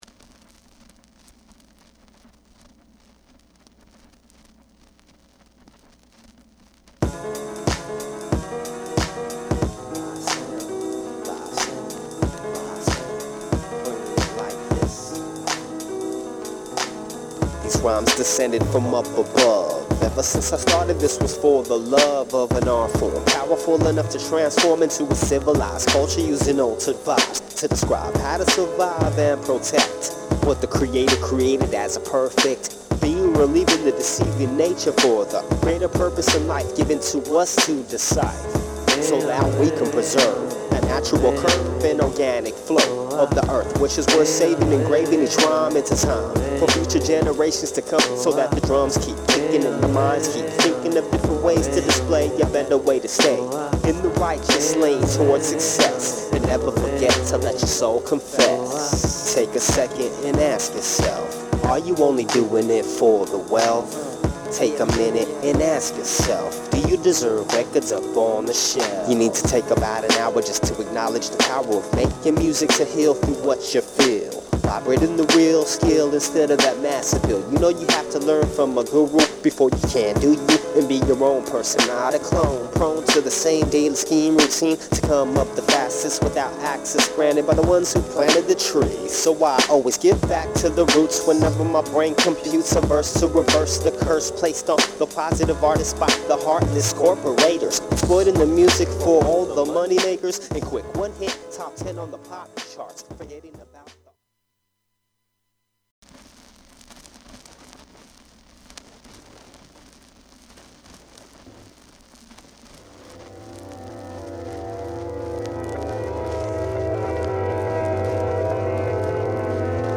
メロウ・ピアノ・ジャジー・チューンの名作。